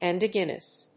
発音記号
• / ˌɛˈndɔdʒɛnʌs(米国英語)
• / ˌeˈndɔ:dʒenʌs(英国英語)